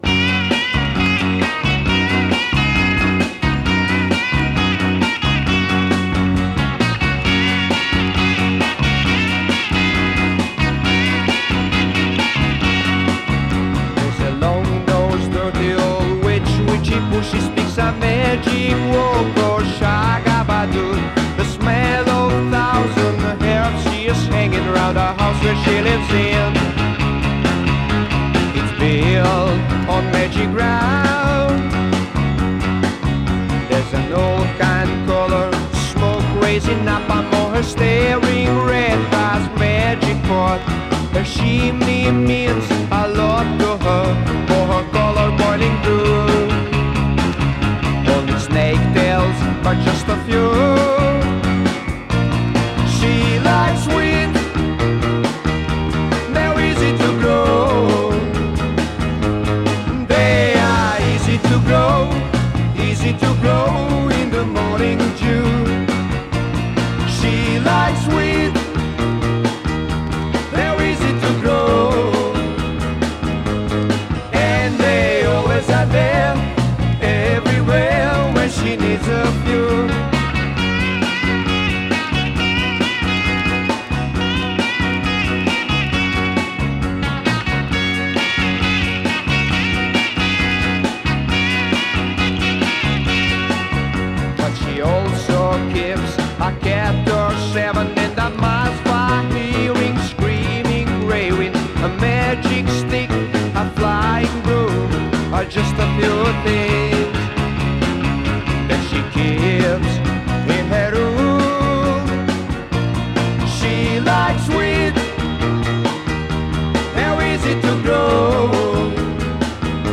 Vocals, Piano, Hammond B3
Vocals, Bass
Drums, Percussion
Vocals, Sacophon, Flute
Vocals, Guitars